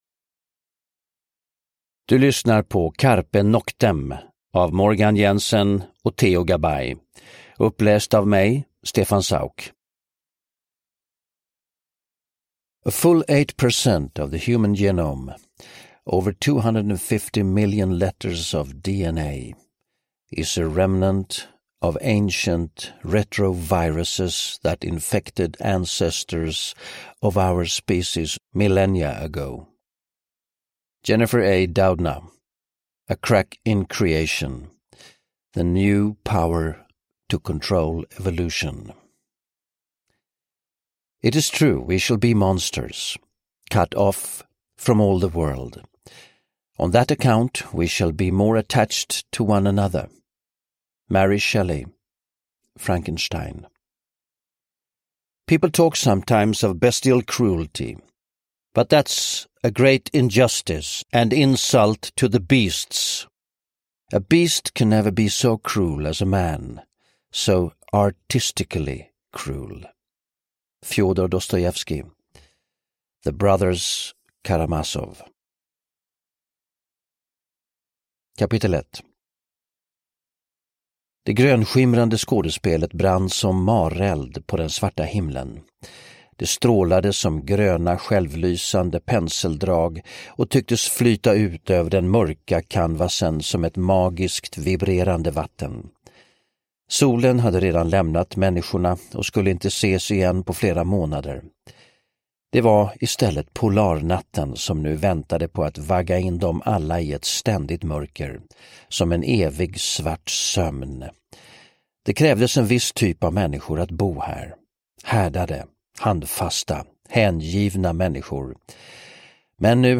Uppläsare: Stefan Sauk
Ljudbok